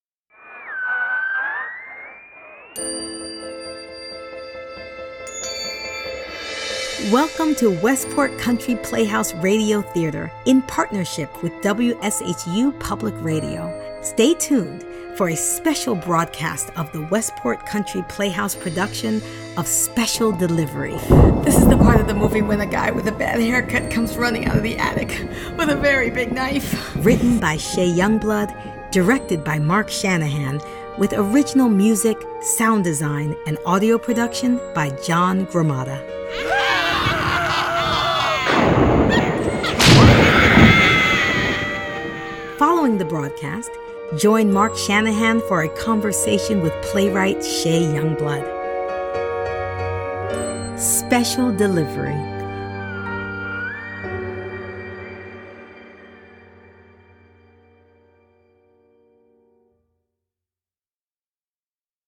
Their destination is an isolated farmhouse full of surprises, just like the ones found late night horror movies. Are those other-worldly voices Asher hears coming from the paintings? Humor and suspense meet in this family-friendly radio play about what it means to discover one’s true gifts.